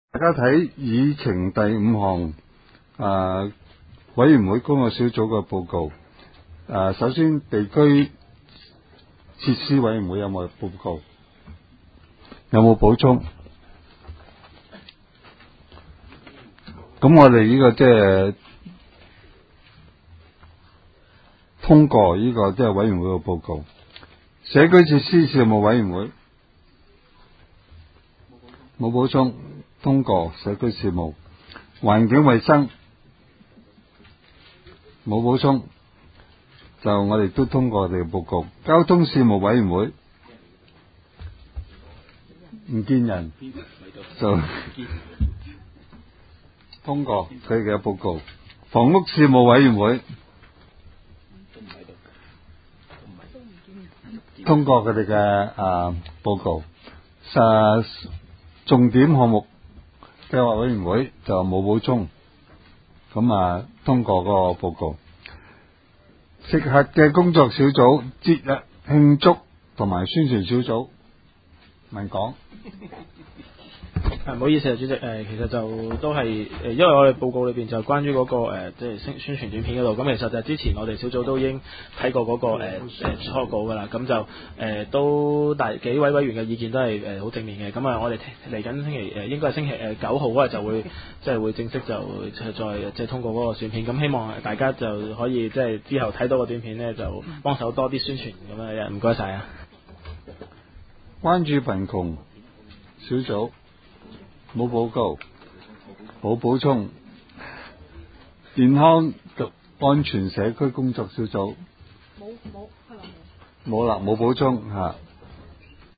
区议会大会的录音记录
深水埗区议会第八次会议
深水埗区议会会议室